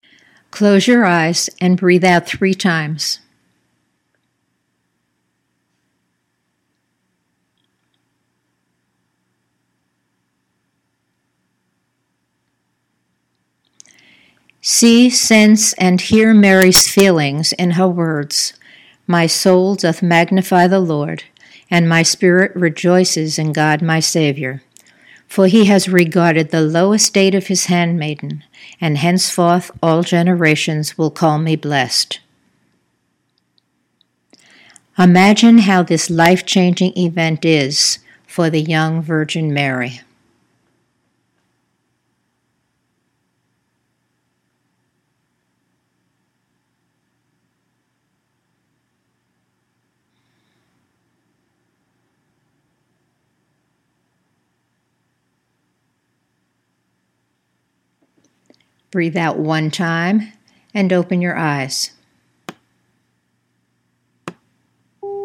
NOW FOR OUR ADVENT NARRATIVE WITH AUDIO IMAGERY (These exercises are not designed for young children.  Adults may use these narratives and/or Imagery as their Advent Wreath Prayers)